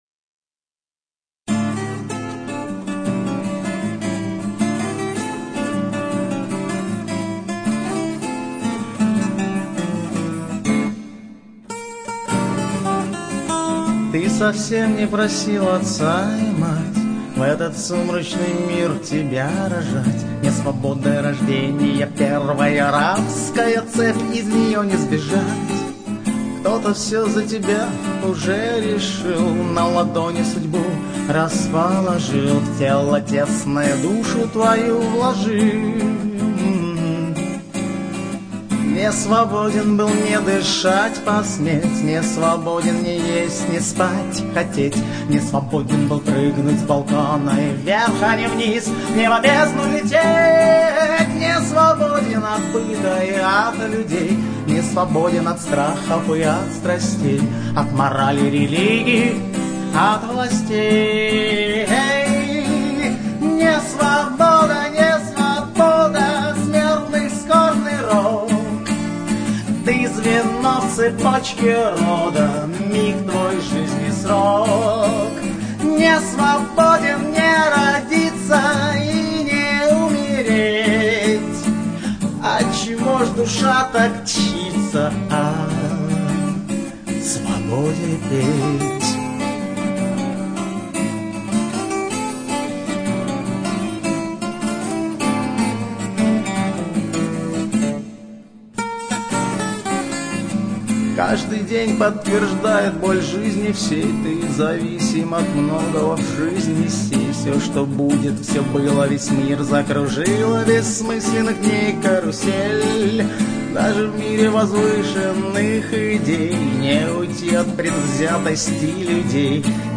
Прекрасный выразительный певец
с крутой профессиональной аранжировкой